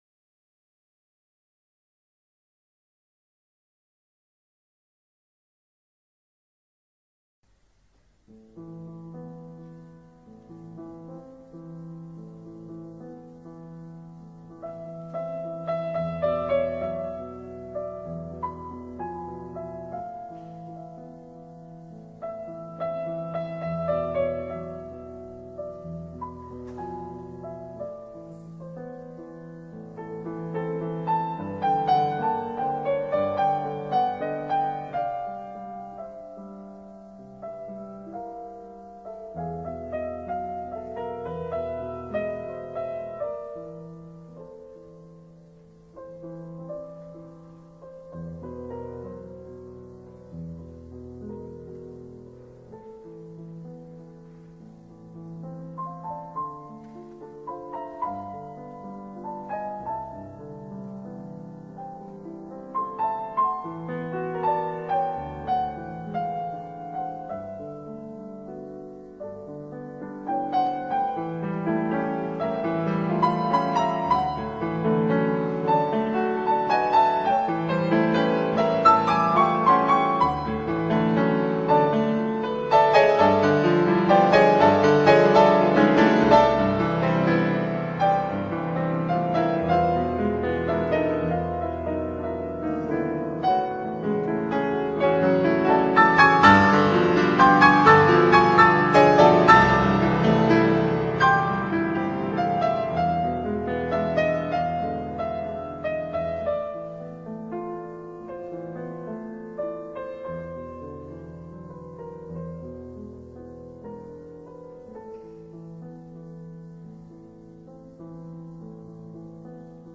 Parmi toutes ces compositions, trois danses argentines pour piano (opus 2 - 1937) dont la deuxième (Danza de la moza Donosa) est à peu près la seule pièce que l'on joue régulièrement en concert. Et pour cause : elle est d'une simplicité étonnante (un seul thème, joué sur cinq notes de la main gauche) et, en même temps, d'une grande tendresse et qui est, sans le paraître, très difficile à jouer correctement.
Et sa fin, contre toute attente est surprenante de modernité.